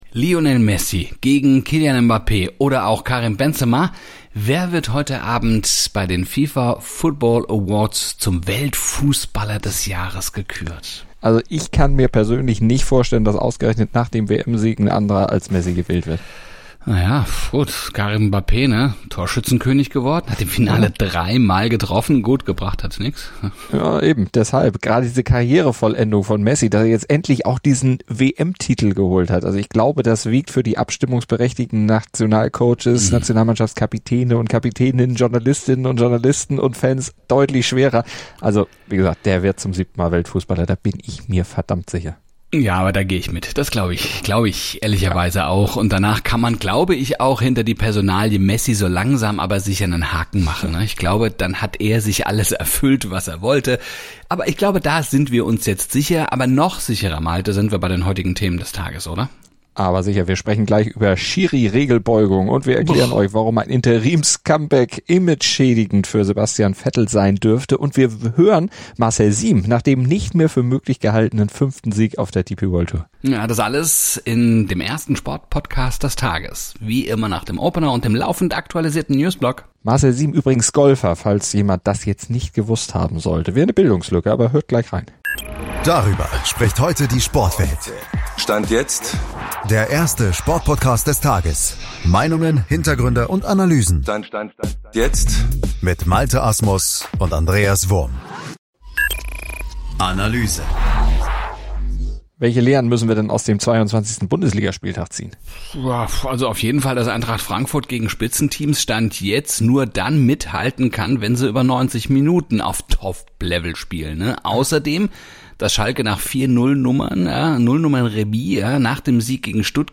Außerdem erklären sie, warum ein Interims-Comeback imageschädigend für Sebastian Vettel sein würde und hören Marcel Siem nach dem nicht mehr für möglich gehaltenen fünften Sieg auf der DP World Tour zu.